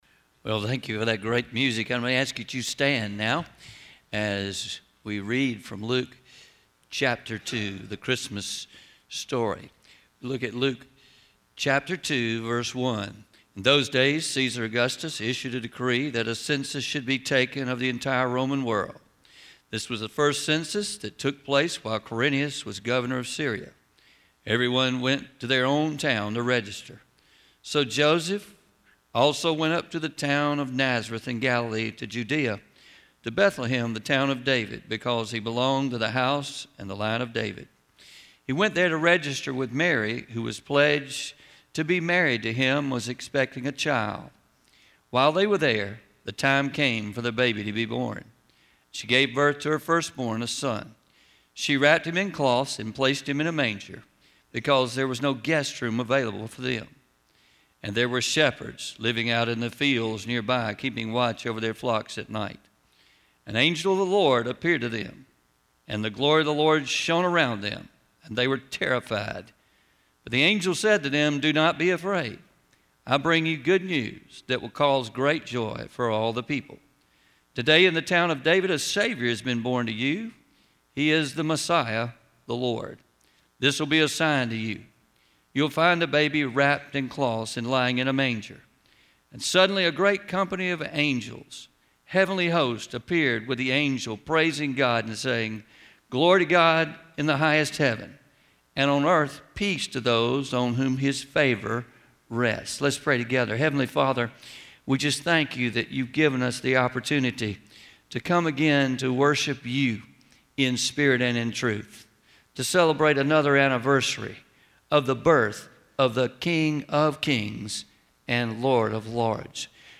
12-24-23am Sermon – The Grace Gift at Christmas